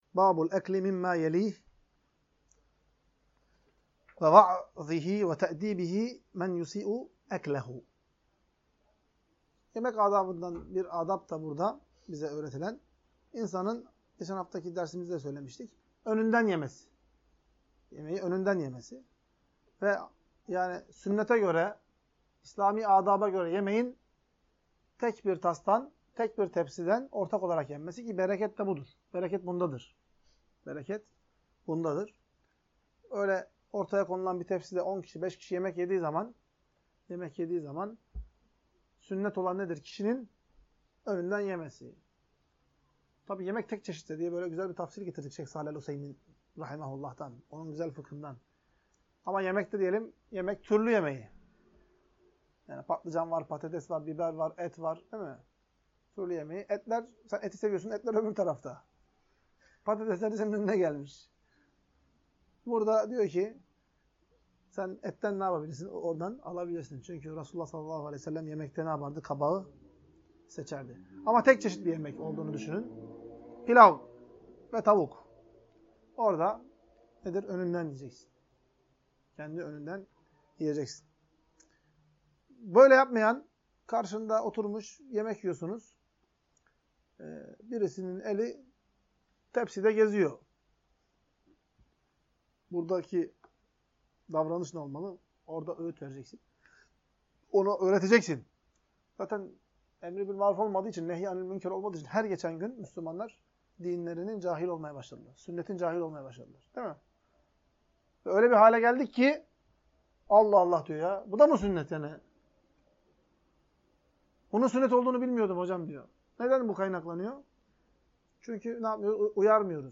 Ders - 5. Bölüm – Kendi Önünden Yemek Ve Yemek Adabına Aykırı Davranana Sofra Adabını Öğretmek